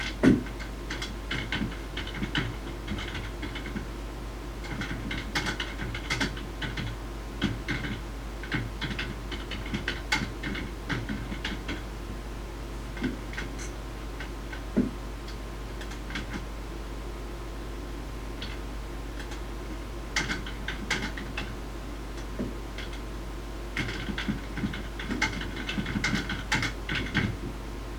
typing-detail-for-web.mp3